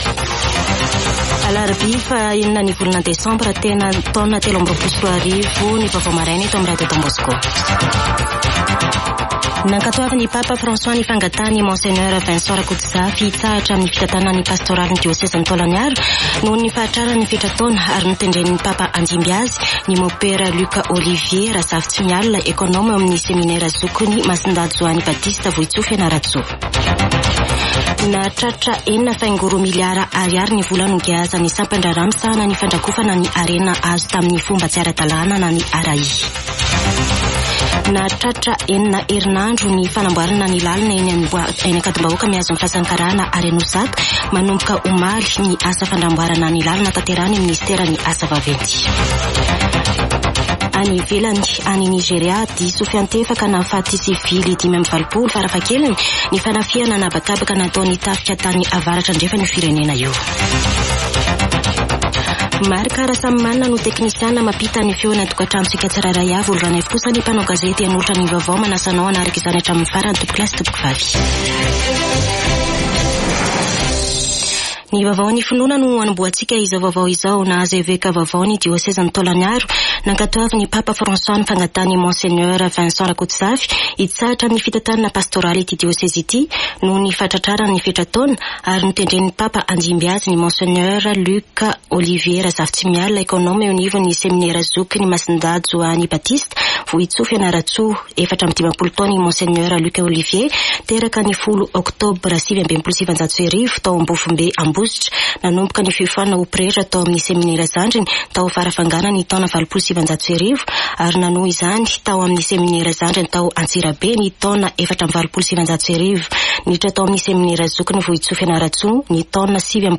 [Vaovao maraina] Alarobia 6 desambra 2023